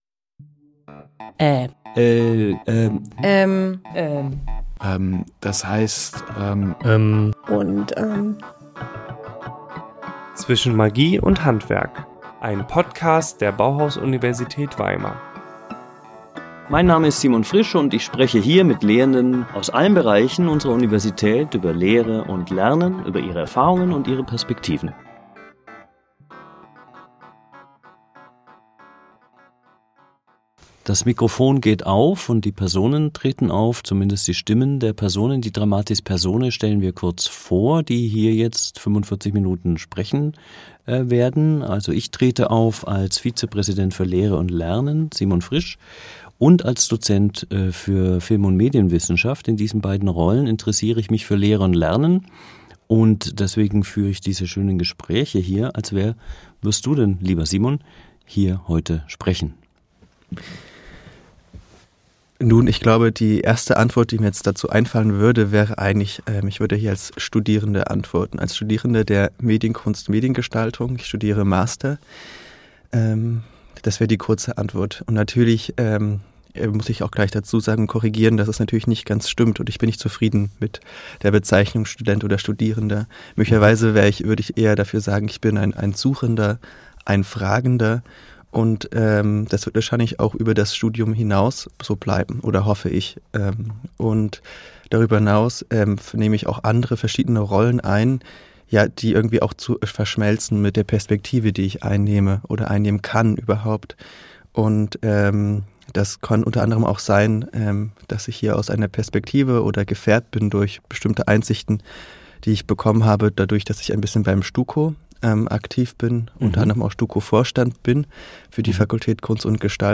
Student der Medienkunst und Mediengestaltung